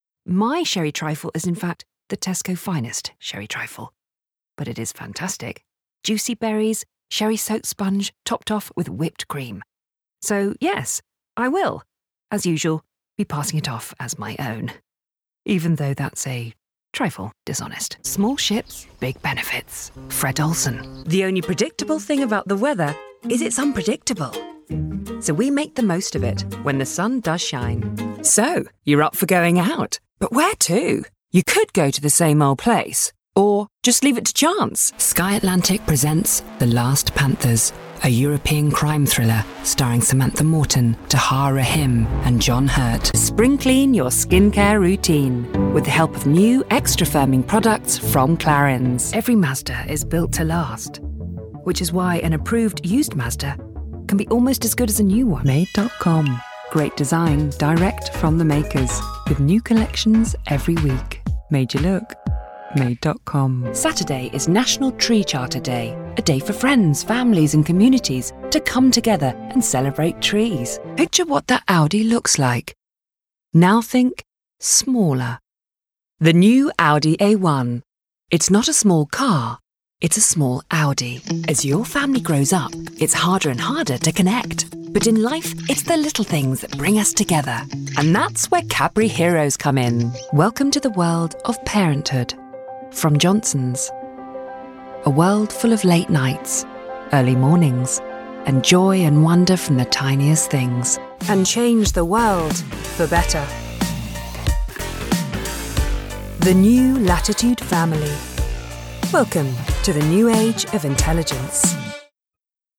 Commercial Reel
RP ('Received Pronunciation')
Promo, Commercial, Upbeat